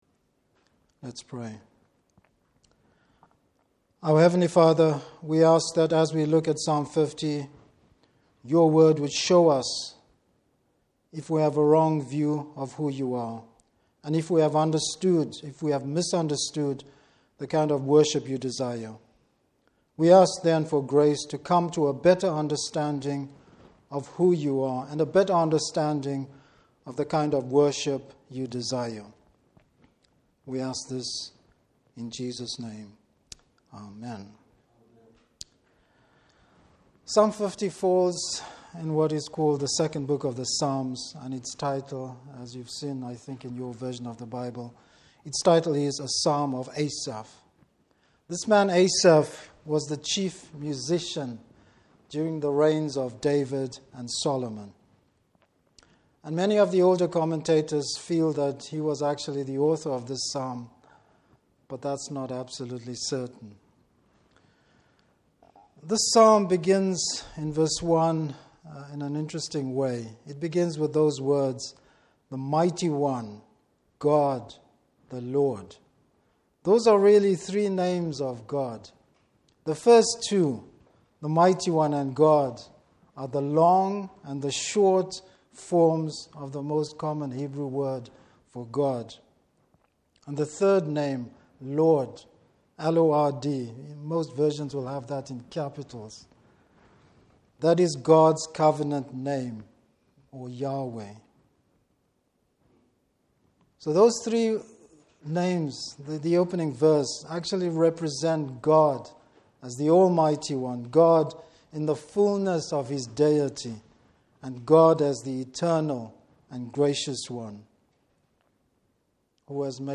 Service Type: Evening Service The Lord has no need of our worship.